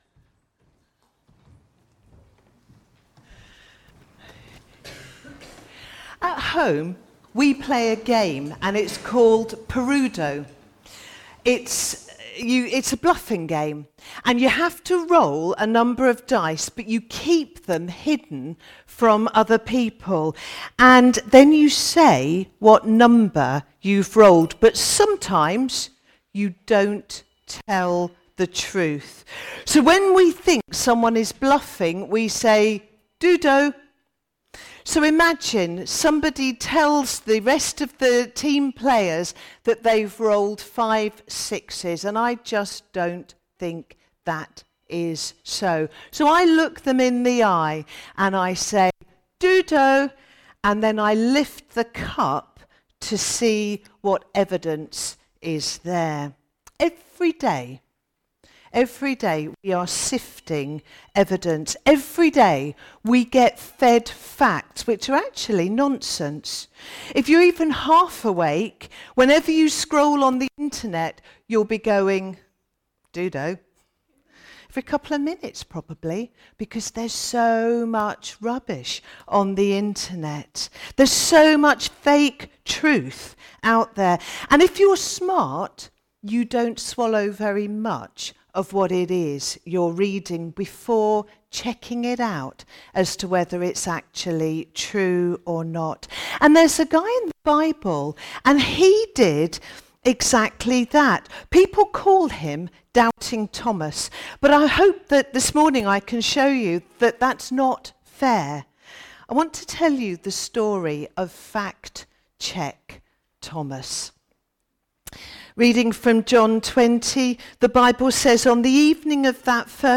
A message from the series "Stand Alone Sermons (2022)."